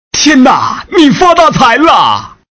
boss_die1.mp3